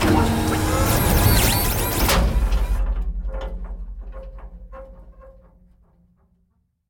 dockingbaydock.ogg